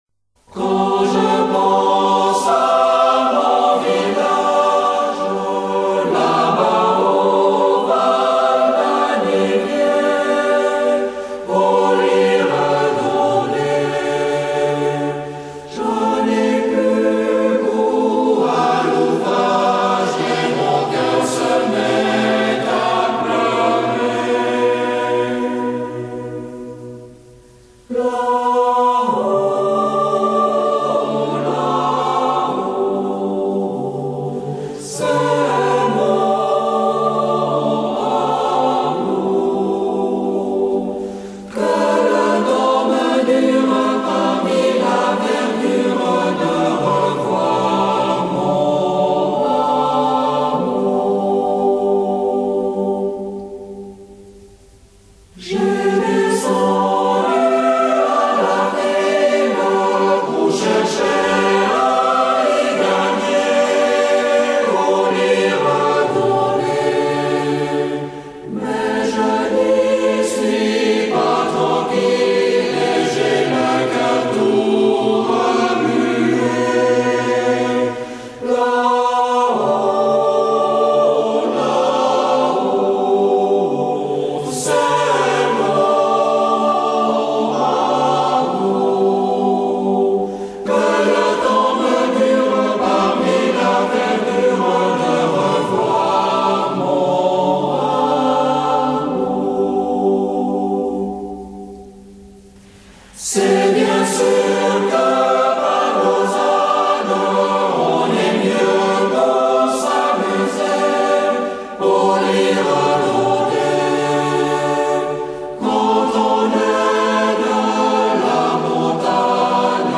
Chœur d’hommes fondé en 1860
Interprété par le Chœur du Léman en : 2008, 2017
H10043-Live.mp3